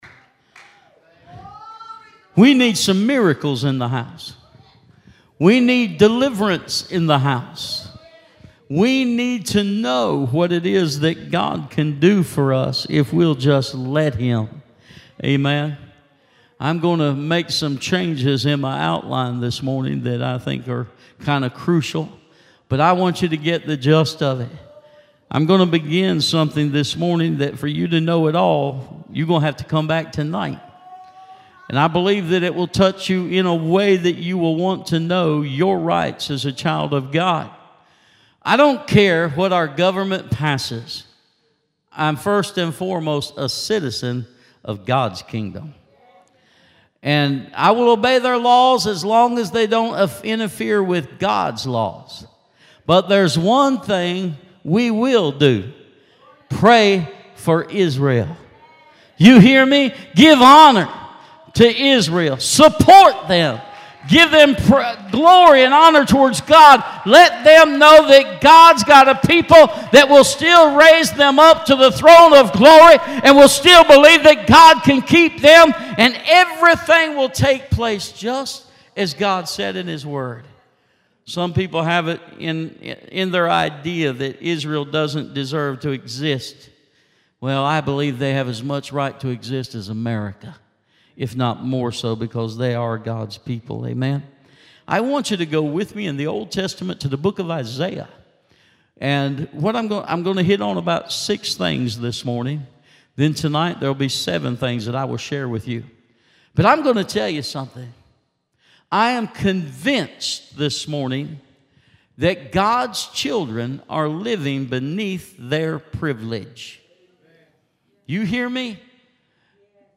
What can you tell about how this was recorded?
Service Type: Sunday Morning Worship Topics: Christian Living , Faith